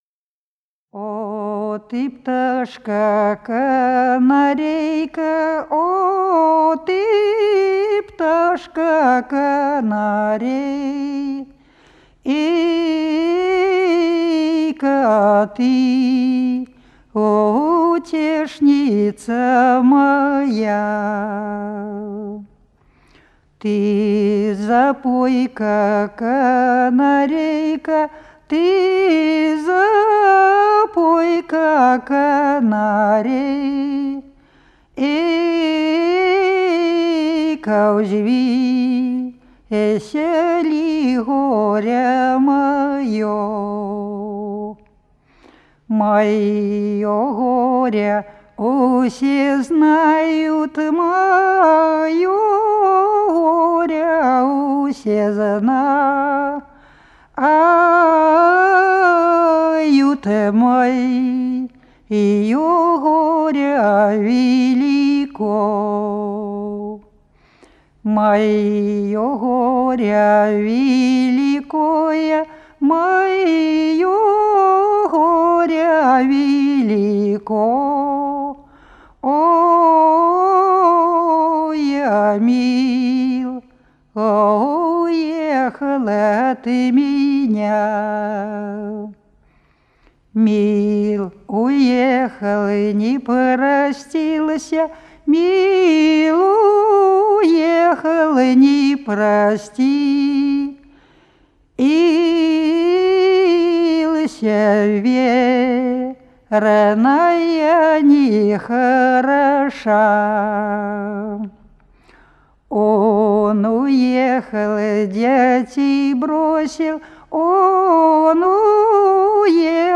Народные песни Смоленской области